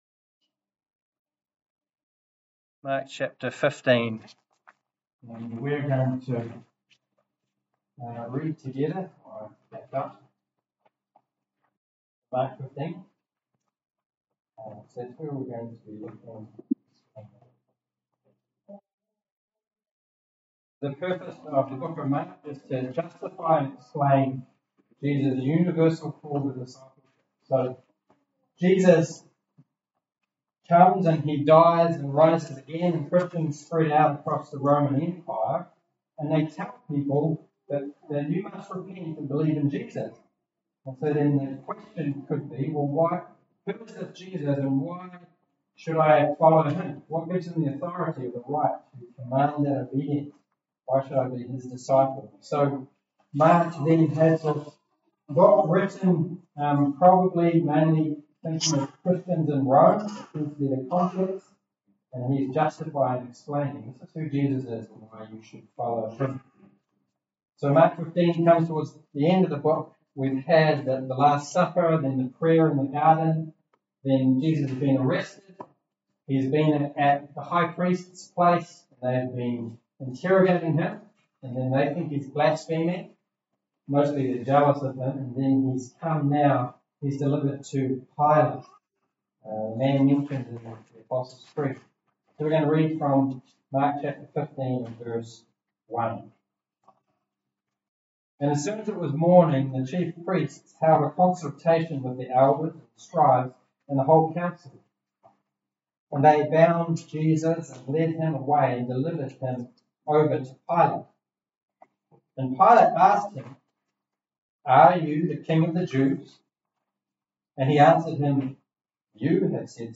**NOTE: This week there was a Technical difficulty, and the recording is very unclear.**
Service Type: Sermon